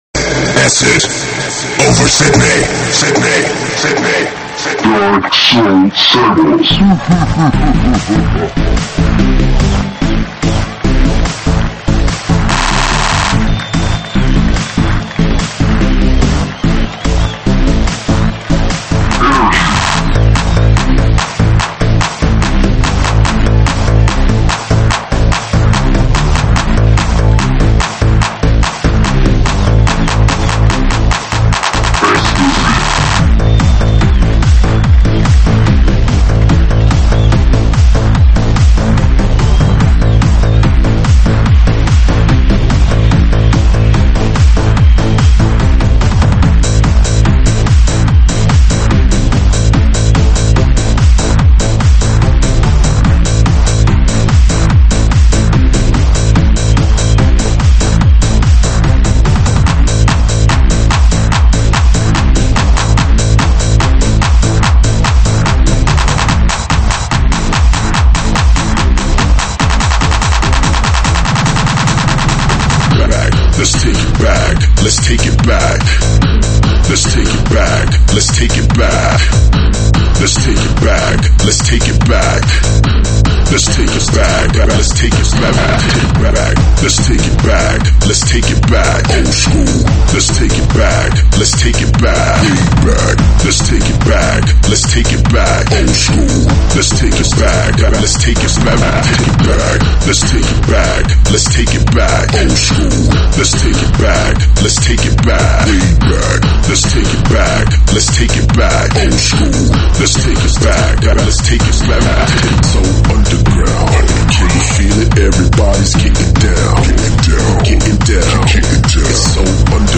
栏目：慢摇舞曲